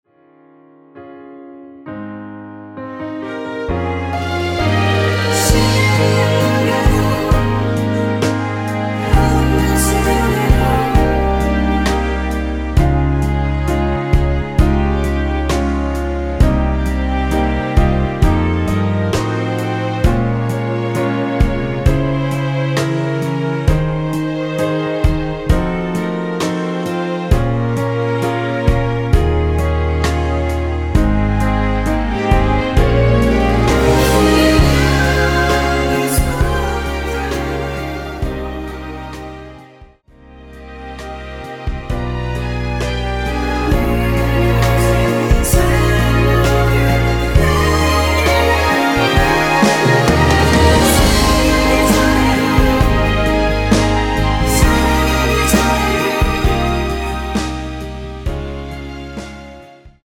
원키에서(+3)올린 코러스 포함된 MR 입니다.(미리듣기 참조)
G#
앞부분30초, 뒷부분30초씩 편집해서 올려 드리고 있습니다.
중간에 음이 끈어지고 다시 나오는 이유는